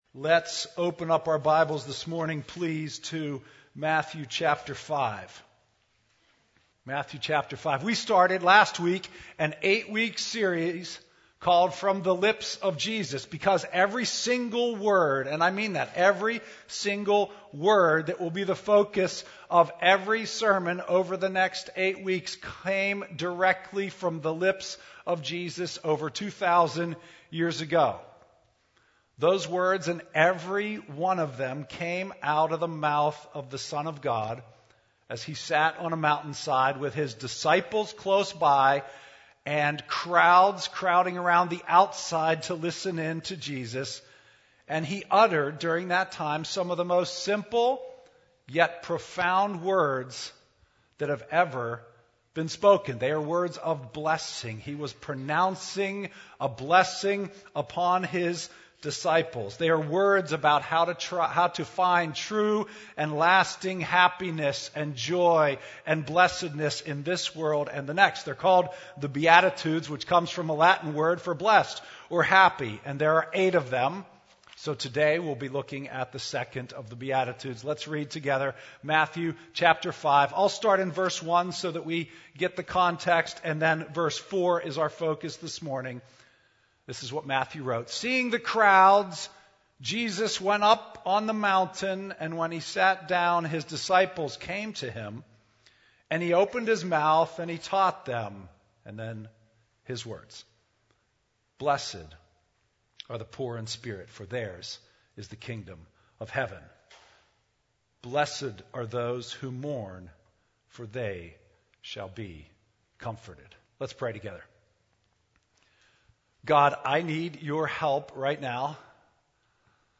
Download the weekly Bible Study that goes with this sermon.